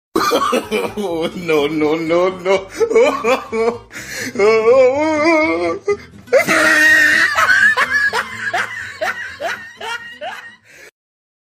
Sound Effects
Risa No No No Jajaja